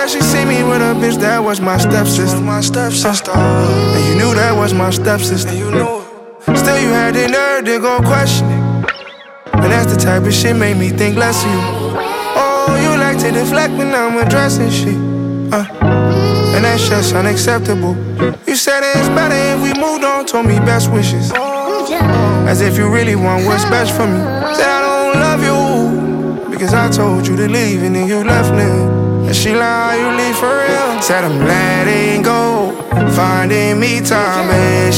Жанр: Хип-Хоп / Рэп / R&B / Соул